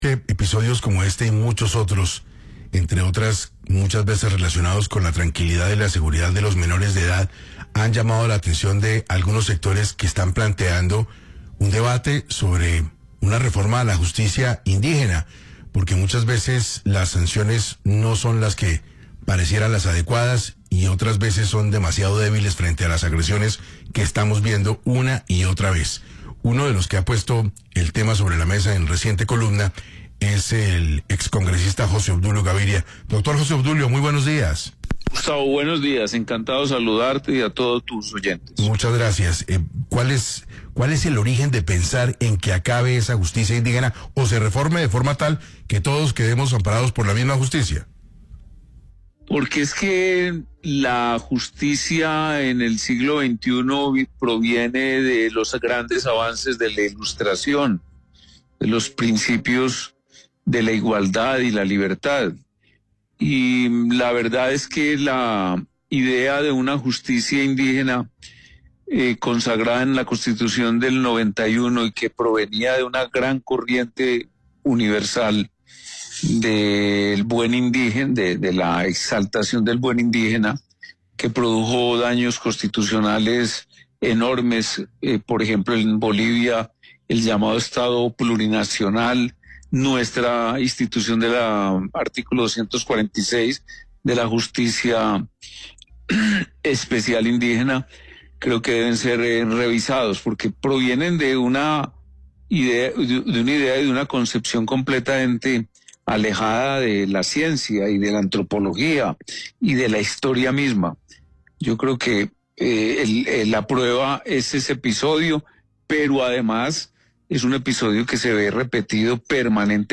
Ante este escenario, el excongresista José Obdulio Gaviria alzó su voz en 6AM de Caracol Radio para proponer una revisión profunda de la justicia especial indígena, consagrada en el artículo 246 de la Constitución Política.